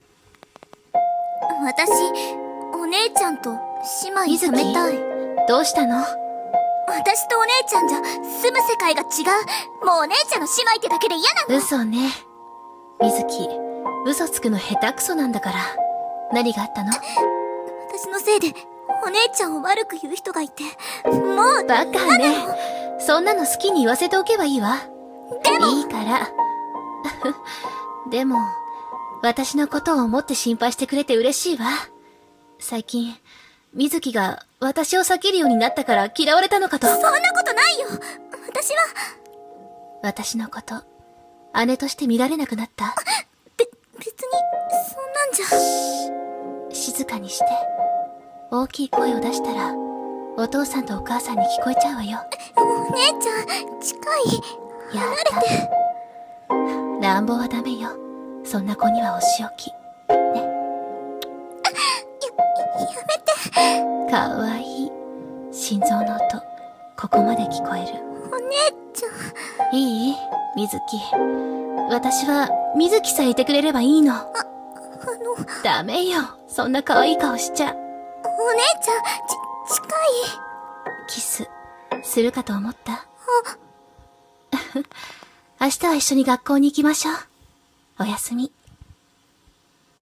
【声劇】指先の微熱【百合】